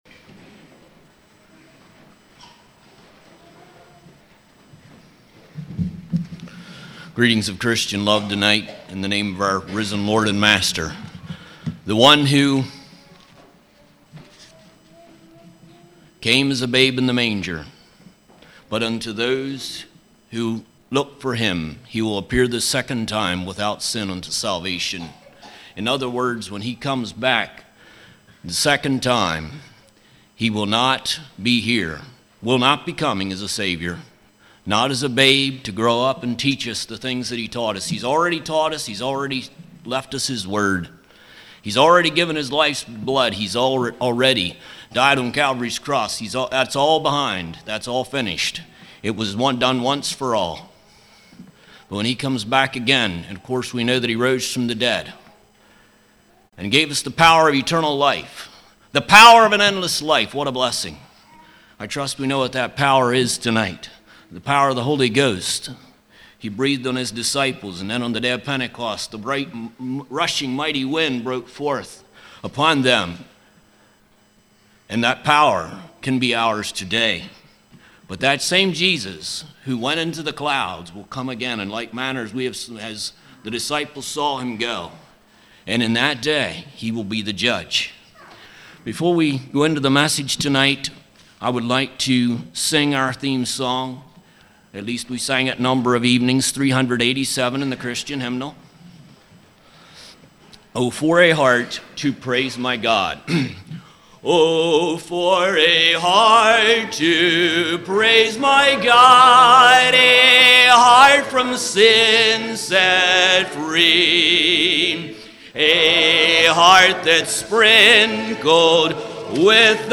Congregation: Calvary
Sermon